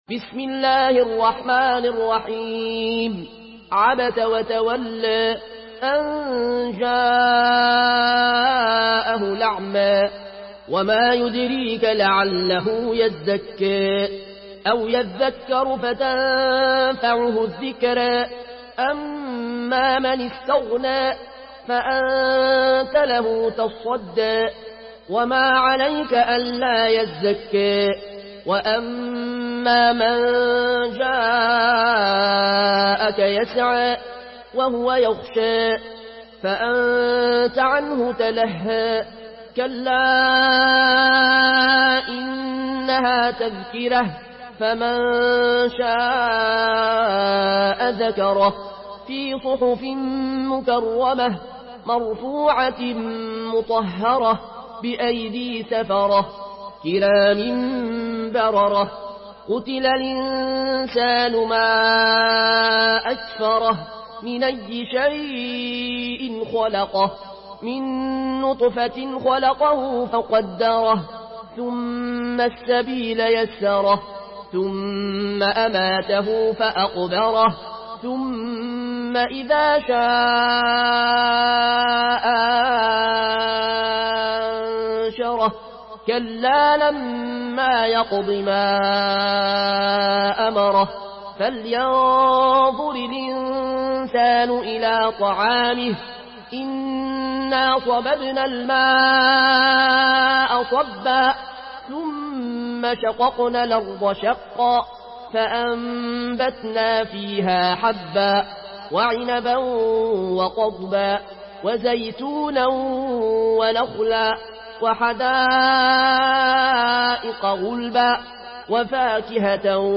Surah Abasa MP3 in the Voice of Al Ayoune Al Koshi in Warsh Narration
Murattal Warsh An Nafi From Al-Azraq way